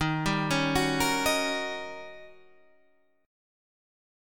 D# 7th Sharp 9th